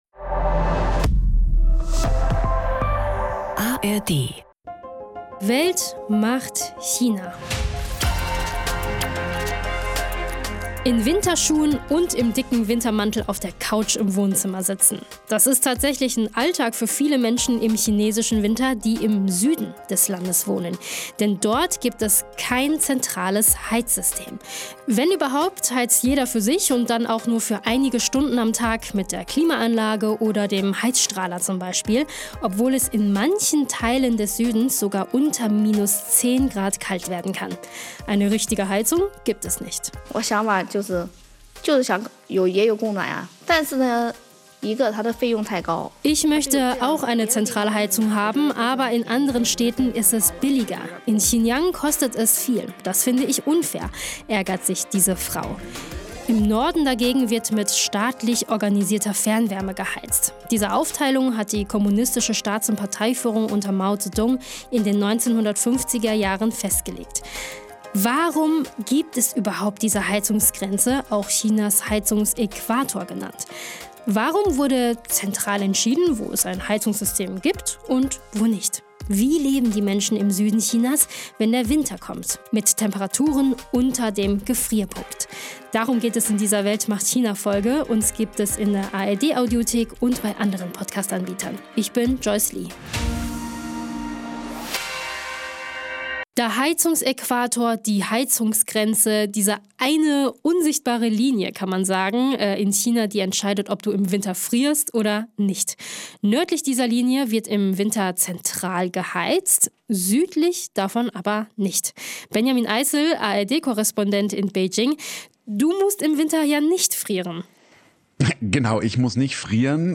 Ein Team aus aktuellen und ehemaligen KorrespondentInnen und China-ExpertInnen schaut nicht nur von außen auf das Land, sondern tief hinein – mit Hintergründen, Analysen, Stimmen und auch einem Blick auf Klischees und Vorurteile, die es in Deutschland eventuell gibt.